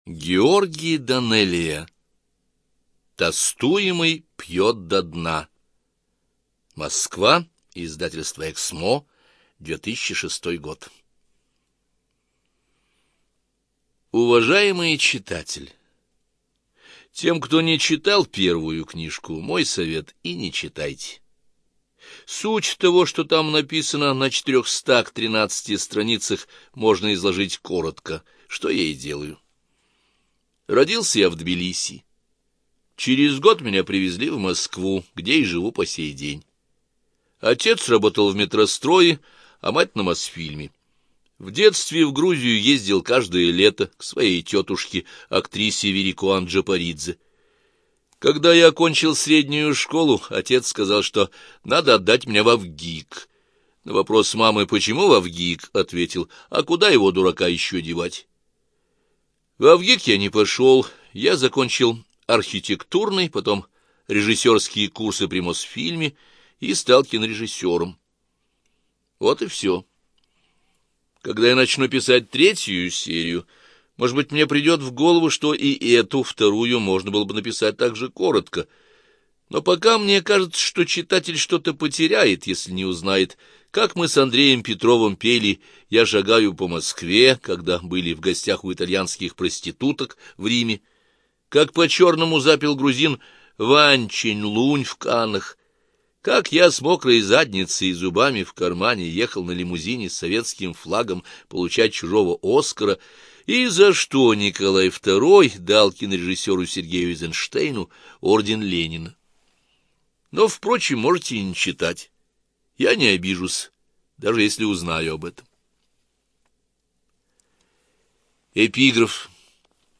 ЖанрБиографии и мемуары, Документальные фонограммы
Студия звукозаписиЛогосвос